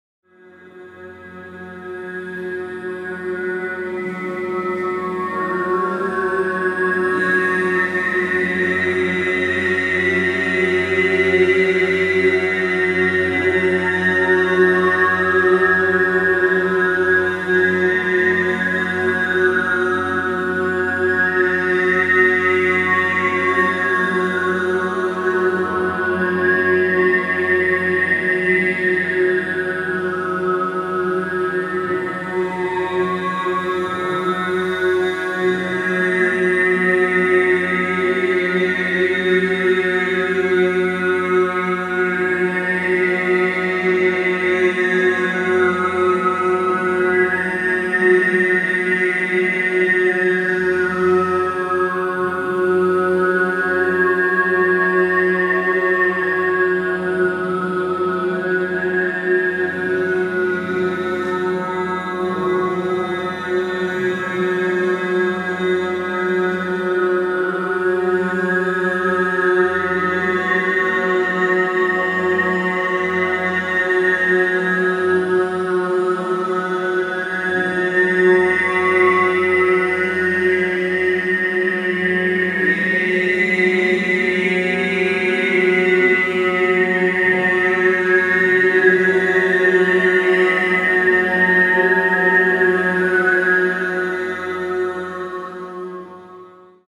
Qui potrai ascoltare alcune parti dei lavori che faremo all'interno del seminario, la durata dei brani è di circa due minuti cadauna
BASE DI M CON CANTO ARMONICO-PARTE.mp3